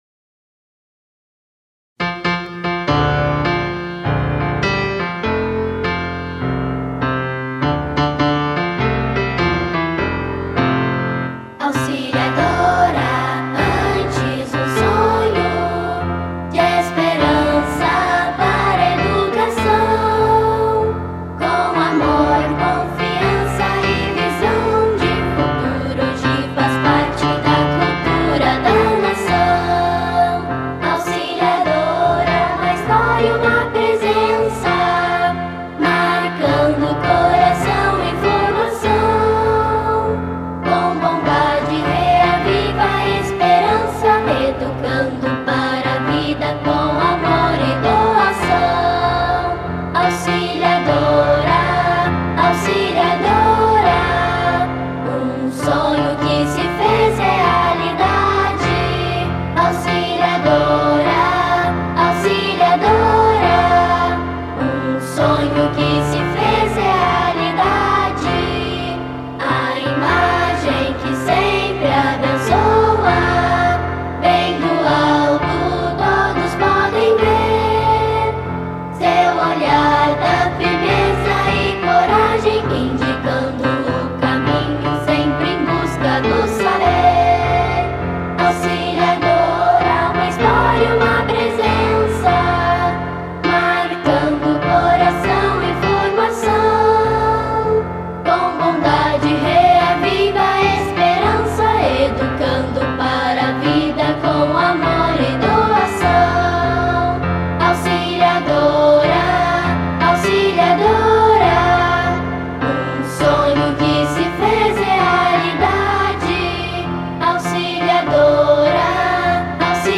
A formação do grupo é composta por alunos das Séries Iniciais
hino-auxiliadora.mp3